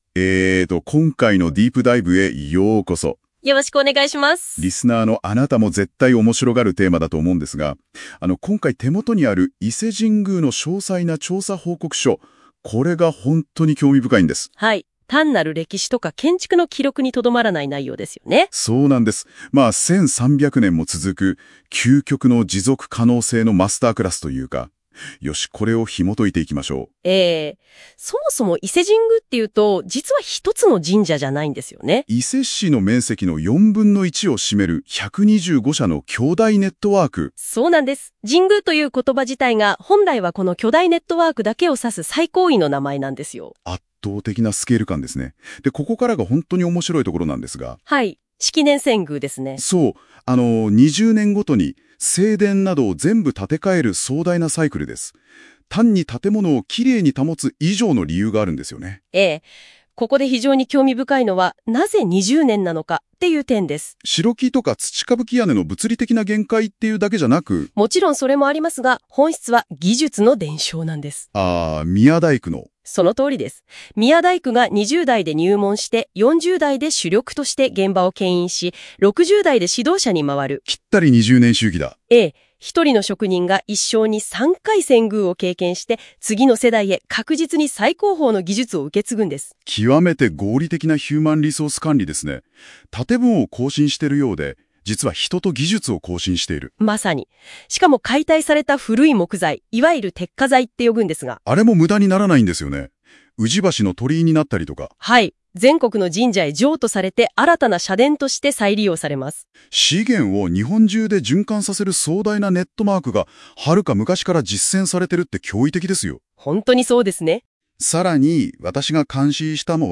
【音声解説】伊勢神宮が20年ごとに生まれ変わる理由
今回は、なぜか音読みが多いです(笑)そこが聴きにくいかな？と思いますが、Geminiが生成する音声解説はいつも為になります。